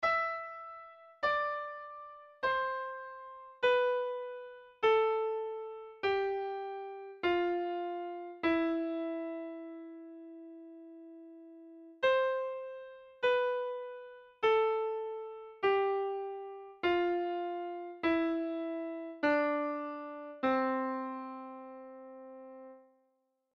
Modo-dórico.mp3